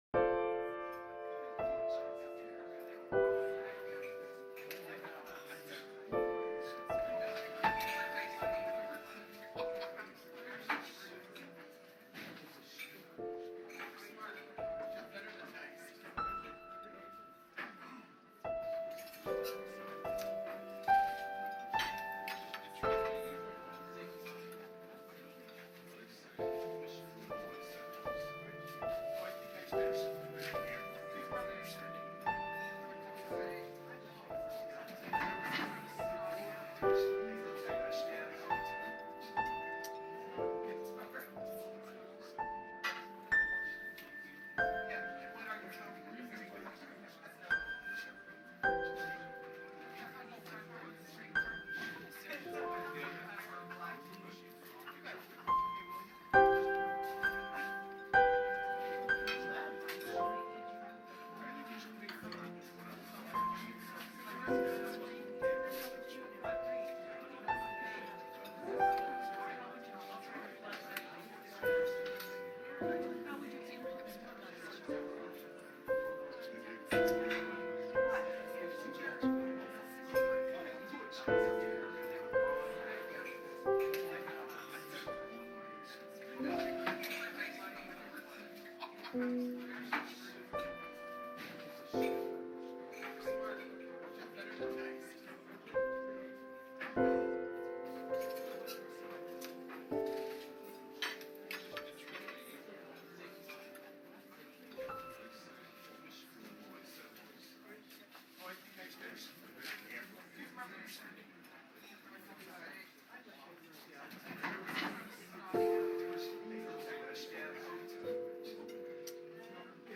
دانلود آهنگ کافه از افکت صوتی طبیعت و محیط
دانلود صدای کافه از ساعد نیوز با لینک مستقیم و کیفیت بالا
جلوه های صوتی